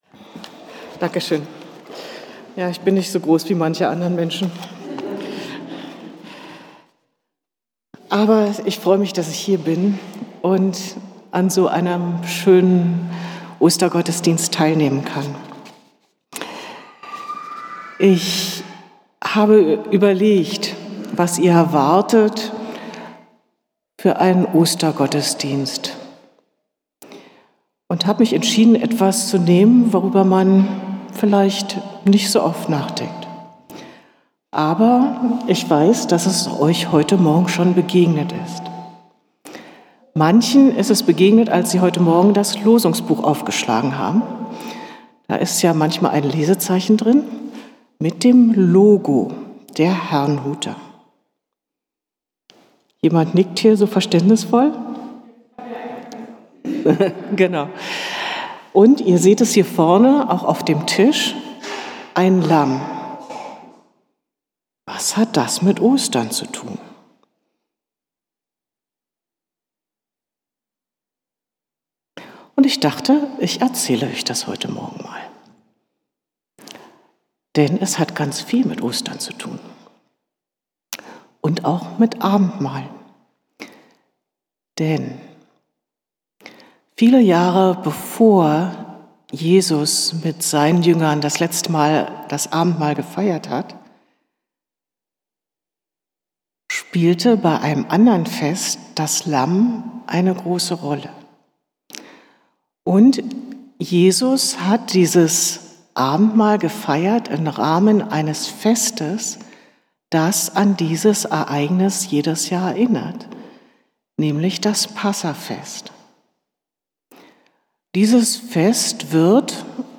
Andacht über das Osterlamm | Bethel-Gemeinde Berlin Friedrichshain